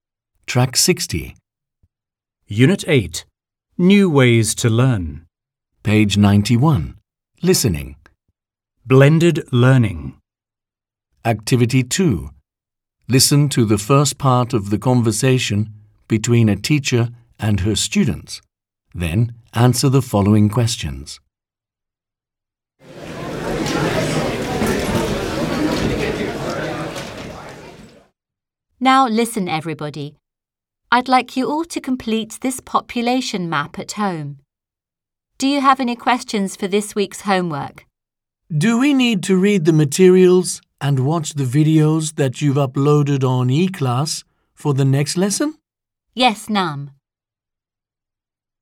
2. Task 2 tiếng Anh 10 Unit 8 Listening: Listen to the first part of the conversation between a teacher and her students, then answer the following questions.